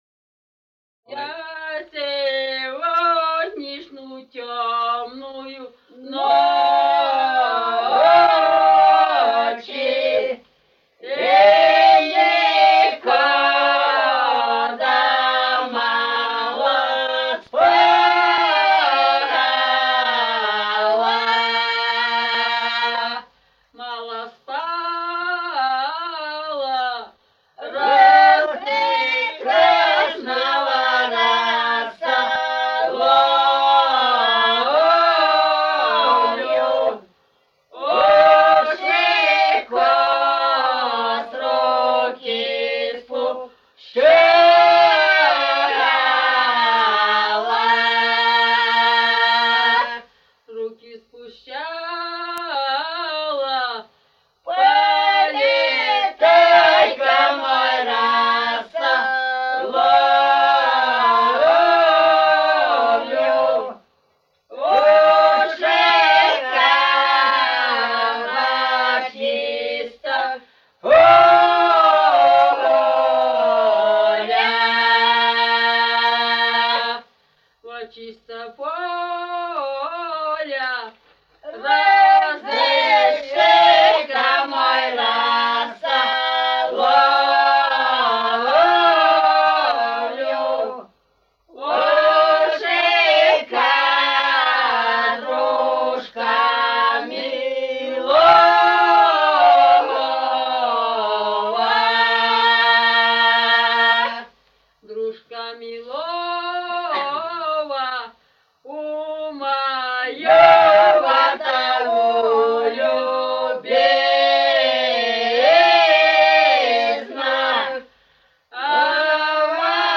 с. Урыль Катон-Карагайского р-на Восточно-Казахстанской обл.